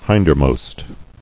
[hind·er·most]